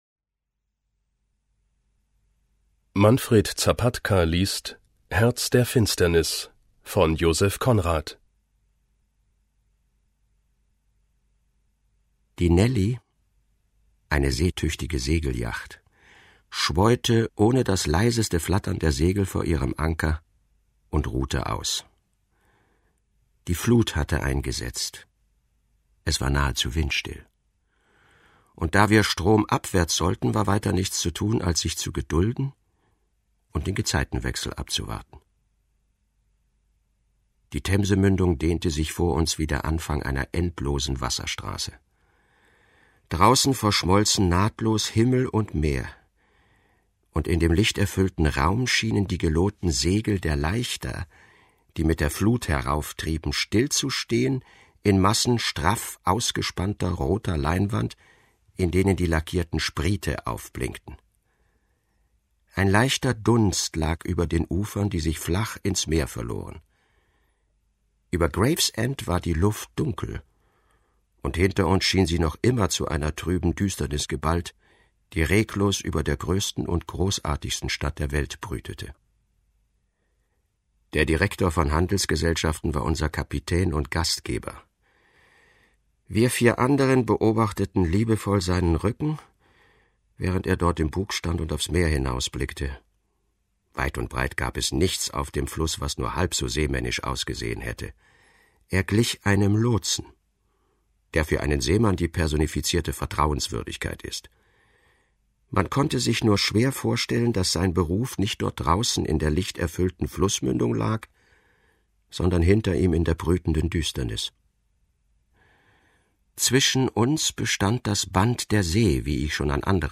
Manfred Zapatka (Sprecher)